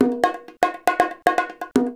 130BONGO 16.wav